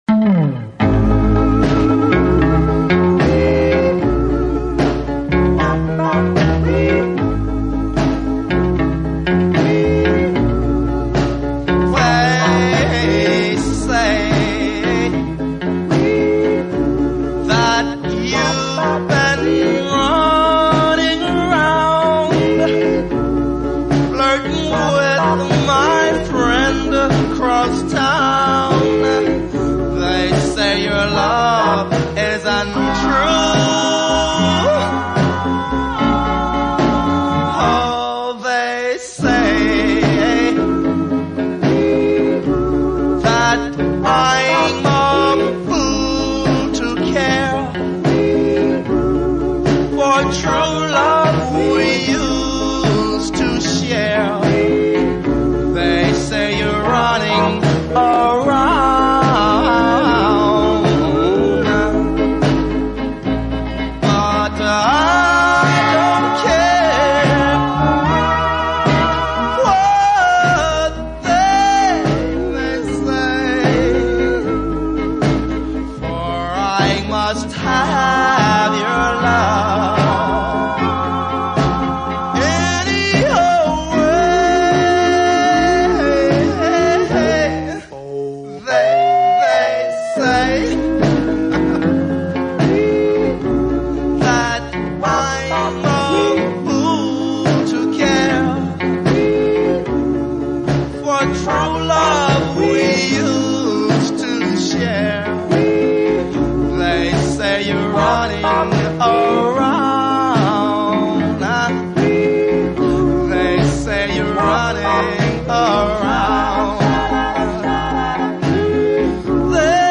Tag: Doo Wop